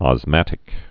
(ŏz-mătĭk)